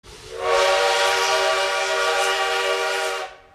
Звуки запикивания мата
Звук гудка парохода для замены мата в видео